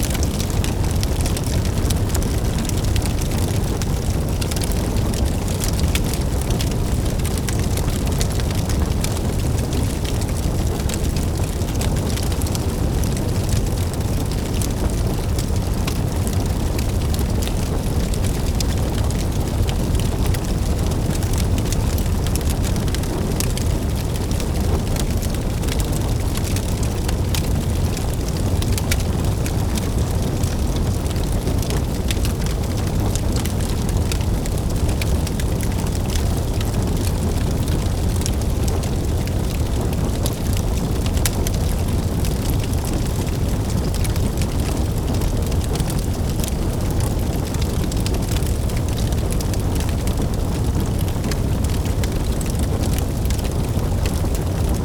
General Fire Loop 2.wav